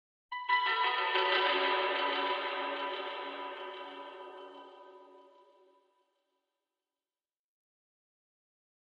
Chinese Koto Arpeggio 2 - Reverb And Rhythmic Echo